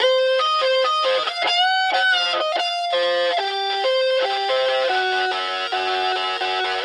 描述：经典
标签： 125 bpm Blues Loops Guitar Electric Loops 2.58 MB wav Key : Unknown
声道立体声